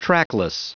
Prononciation du mot trackless en anglais (fichier audio)
Prononciation du mot : trackless